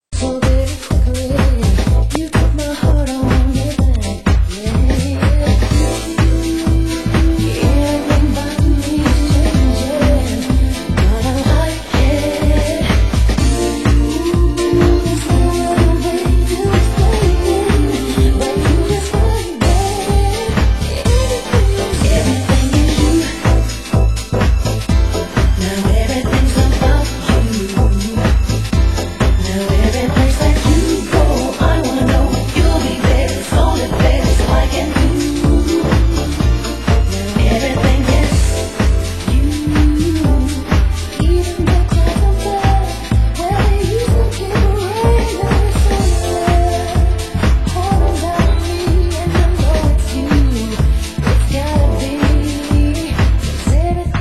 Genre UK House